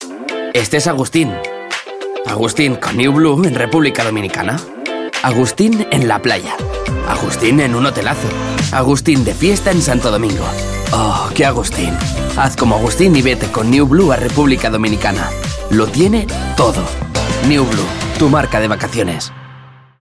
sehr variabel
Jung (18-30)
Commercial (Werbung)